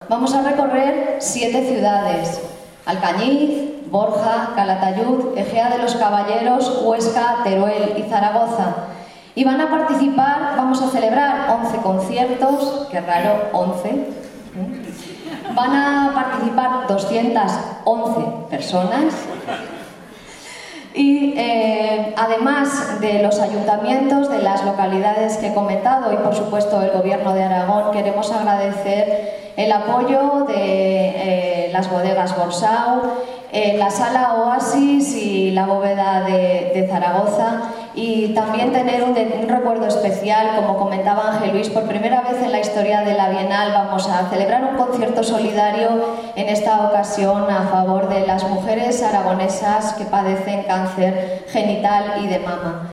durante la presentación del concierto inaugural de esta Bienal, que tuvo lugar en el Teatro Marín de Teruel